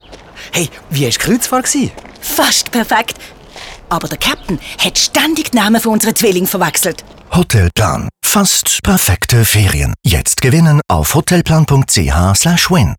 6b_Radio_Hotelplan_Kreuzfahrt.mp3